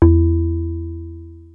JUP.8 E2   3.wav